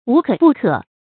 無可不可 注音： ㄨˊ ㄎㄜˇ ㄅㄨˋ ㄎㄜˇ 讀音讀法： 意思解釋： ①無所不可，都能做到。